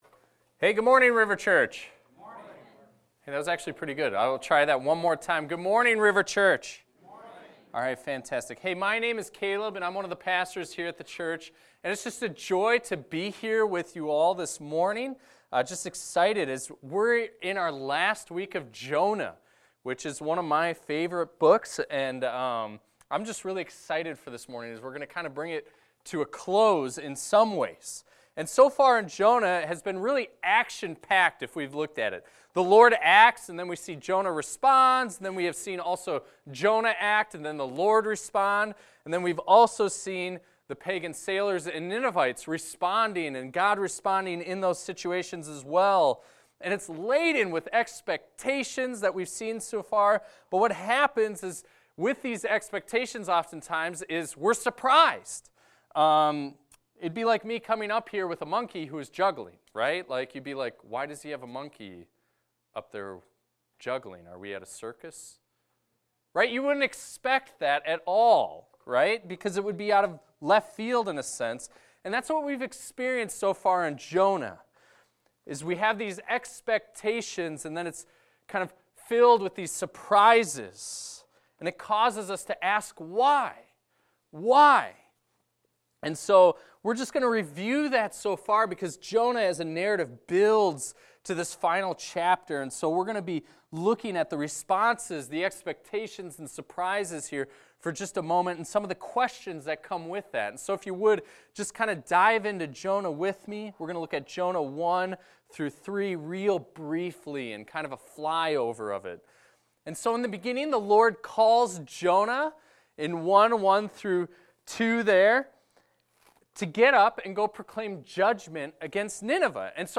This is a recording of a sermon titled, "Chapter 4."